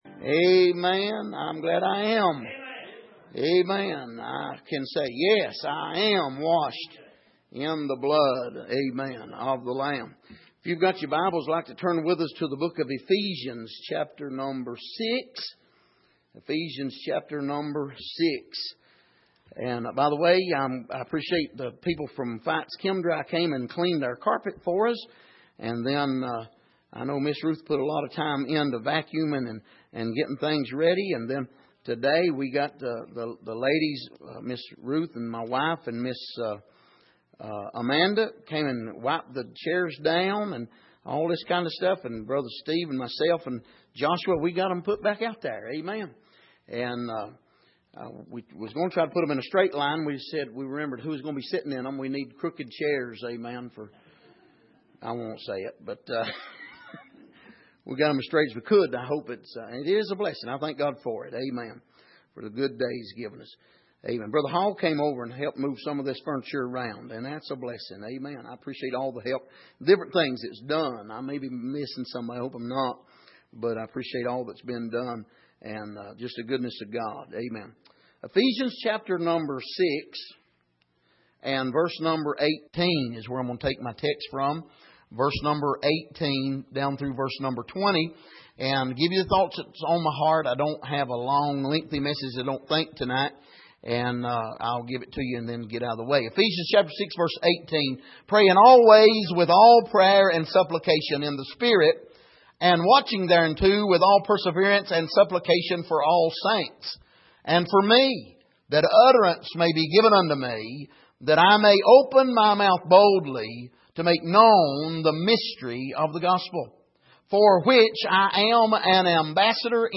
Miscellaneous Passage: Ephesians 6:17-20 Service: Midweek Prophetic Utterances « Where Is The God of Judgment?